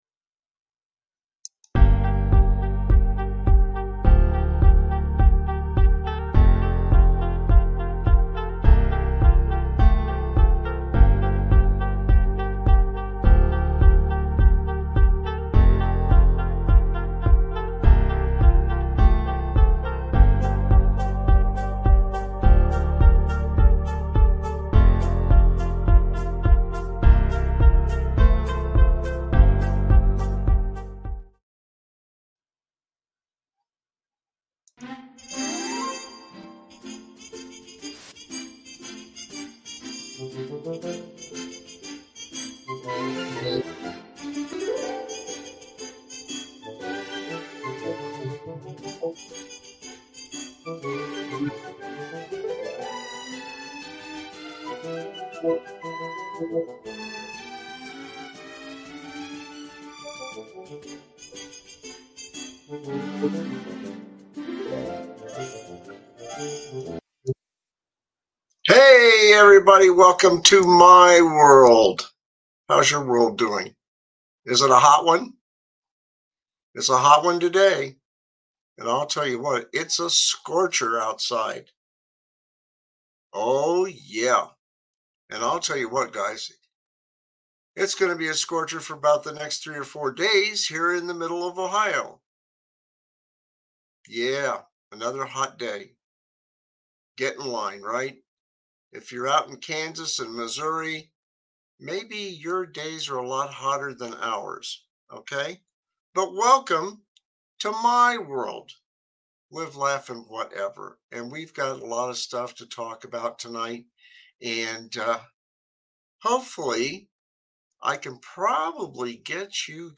My WorldLive, Laff, Whatever is a satirical talk show that tackles the absurdities of life with ahealthy dose of humor.